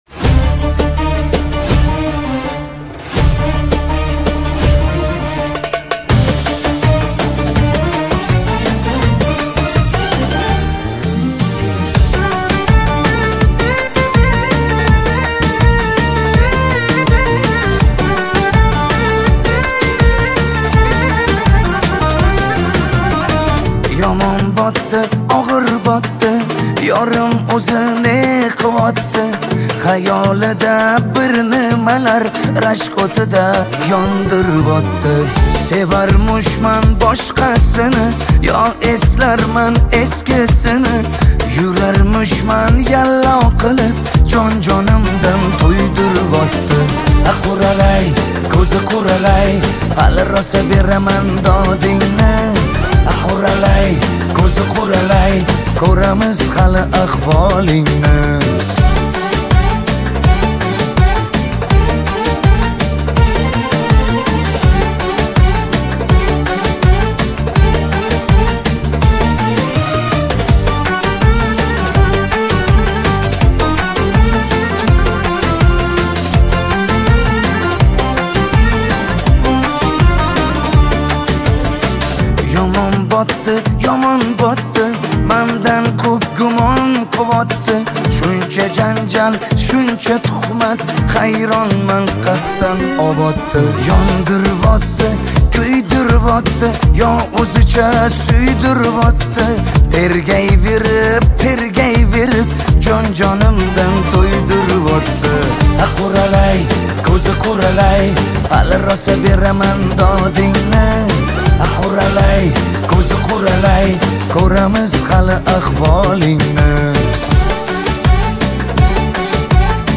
Узбекская музыка